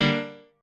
piano8_3.ogg